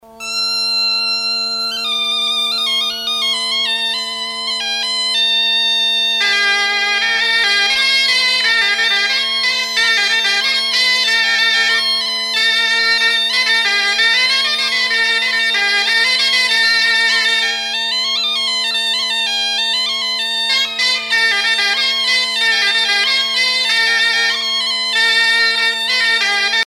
danse : rond de Saint-Vincent
Pièce musicale éditée